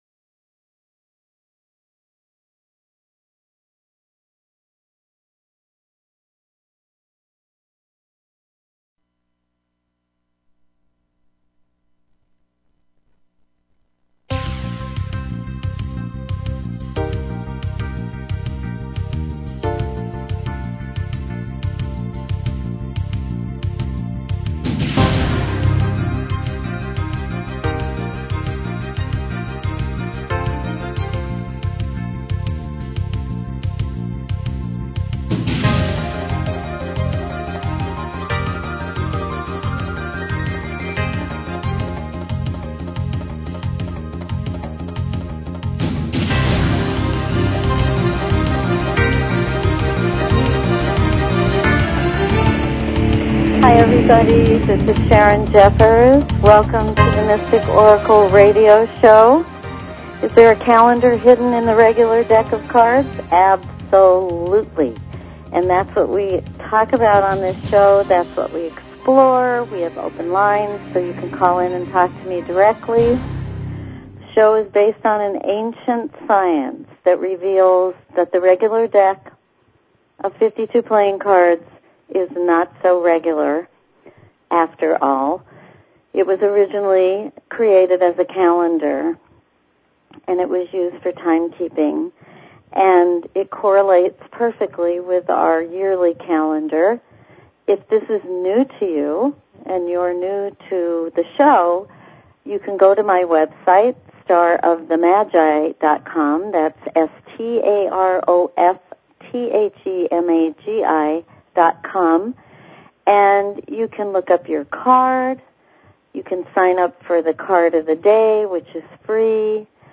Talk Show Episode, Audio Podcast, The_Mystic_Oracle and Courtesy of BBS Radio on , show guests , about , categorized as
Open lines for calls.